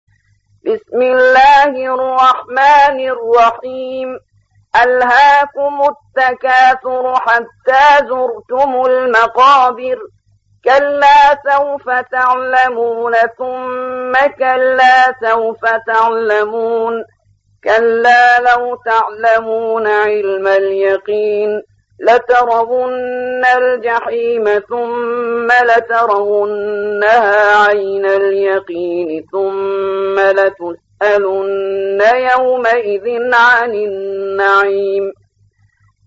102. سورة التكاثر / القارئ